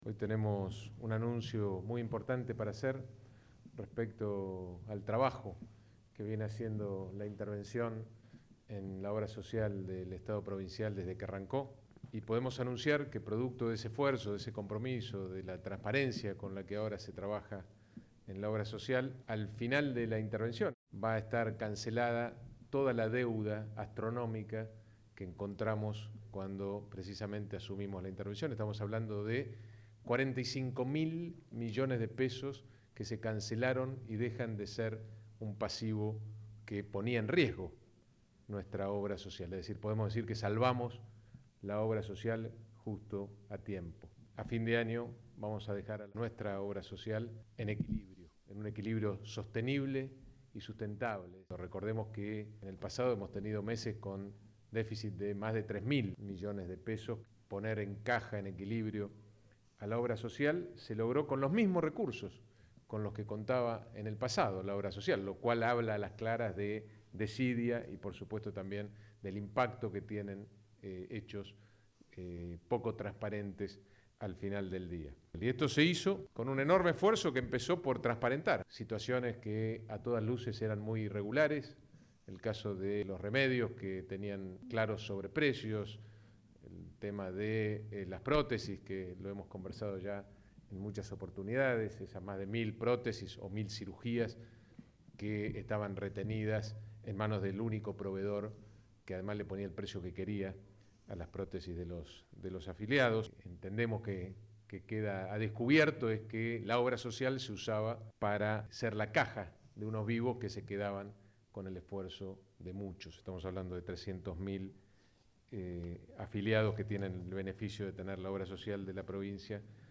El gobernador Rogelio Frigerio encabezó este martes una conferencia de prensa donde anunció que el Instituto de Obra Social de la Provincia de Entre Ríos (Iosper), concluirá su etapa de intervención con un resultado histórico: la cancelación total de su deuda, estimada en 45.000 millones de pesos.
El mandatario estuvo acompañado en la rueda de prensa que se realizó en la Sala de Periodistas de Casa de Gobierno, por el interventor del organismo, Mariano Gallegos y el ministro de Gobierno y Trabajo, Manuel Troncoso.
ESCUCHAR GOBERNADOR ROGELIO FRIGERIO